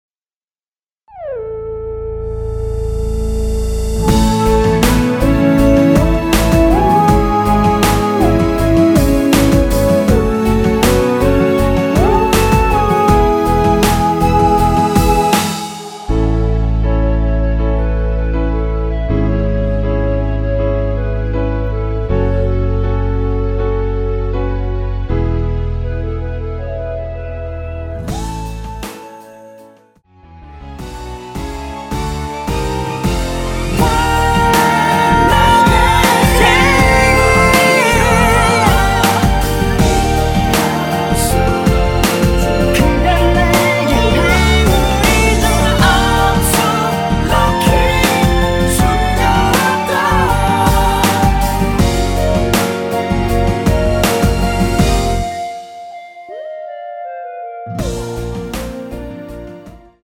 원키 멜로디와 코러스 포함된 MR입니다.(미리듣기 확인)
앞부분30초, 뒷부분30초씩 편집해서 올려 드리고 있습니다.